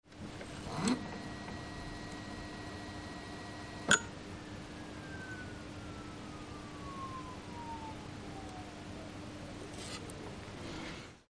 I made a rec (normalized/boosted + 20dB louder, hope you can hear it) so you can hear to capstan motor's little noise. It's really hard to be audible but I don't know if that kind of noise (note that ~1900 Hz freq it produces & that "fade out / airplane landing" sound when I de-press the tape sensor switch) is normal.
AT RMX64 CAPSTAN SOUND.mp3